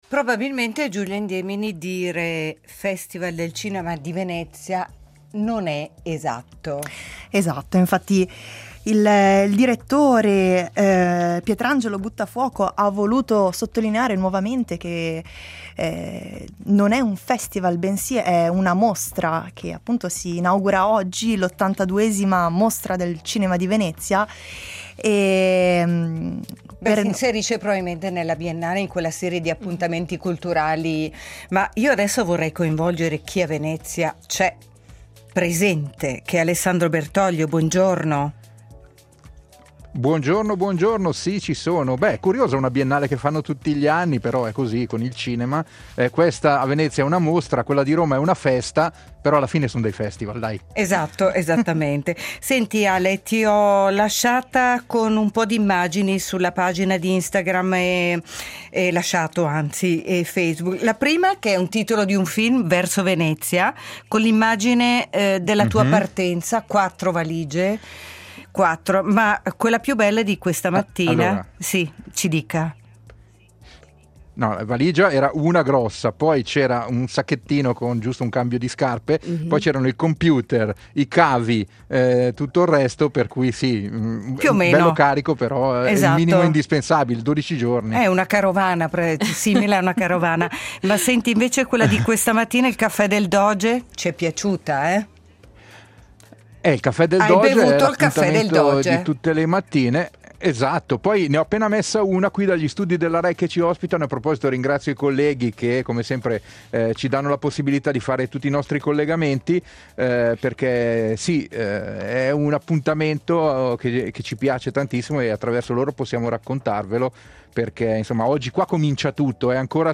In diretta da Venezia